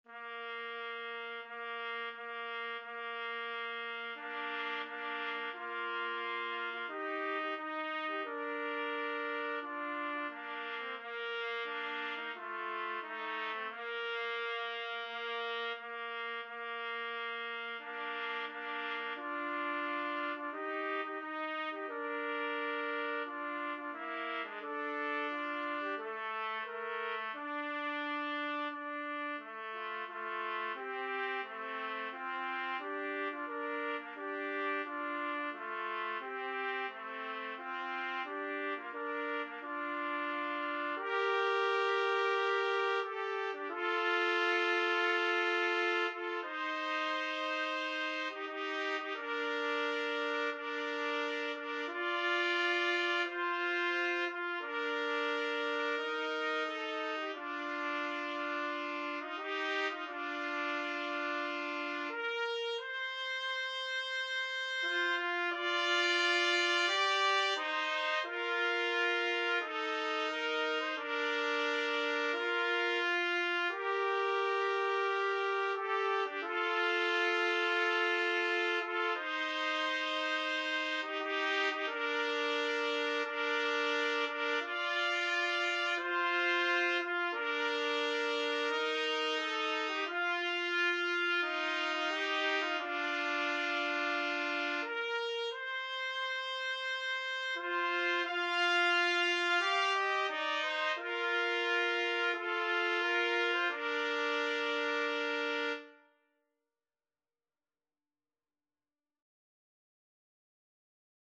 Christmas
Andante =c.88
Trumpet Duet  (View more Intermediate Trumpet Duet Music)